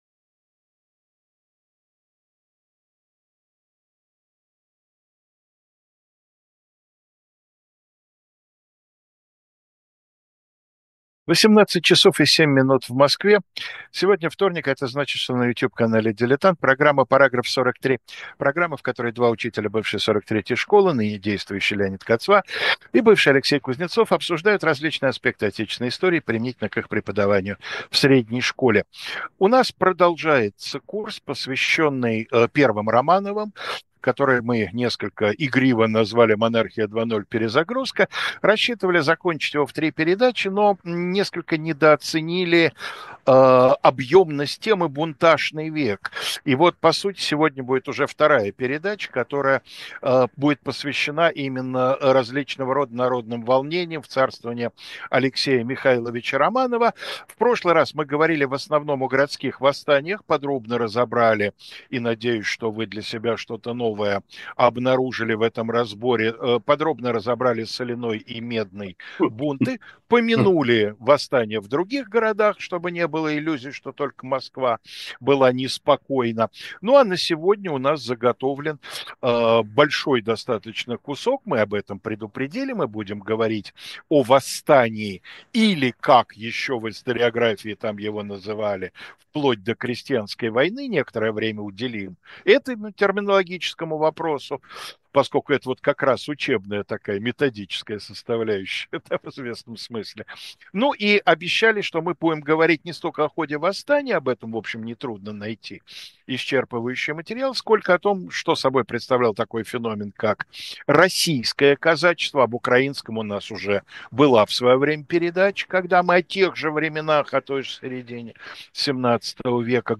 Программа, в которой два историка рассуждают о том, как различные сюжеты истории могли бы преподаваться в школе